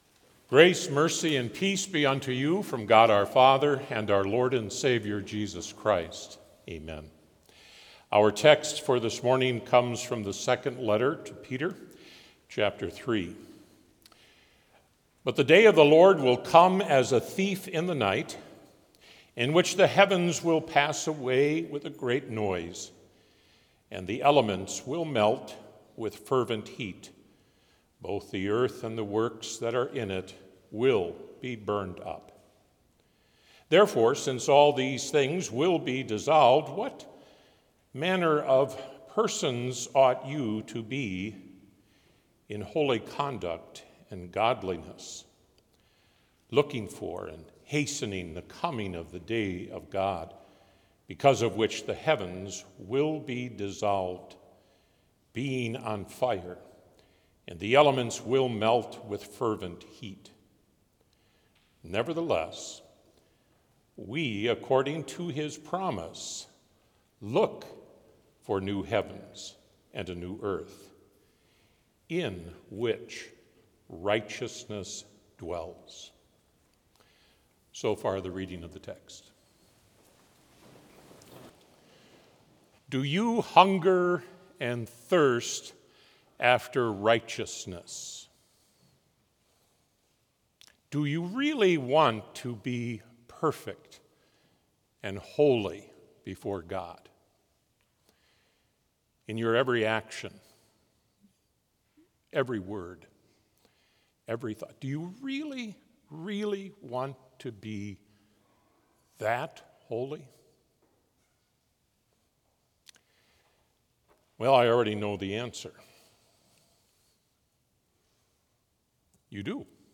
Complete service audio for Chapel - Thursday, November 13, 2025
Prelude Hymn 532 - Jesus Christ, My Sure Defense